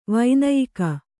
♪ vainayika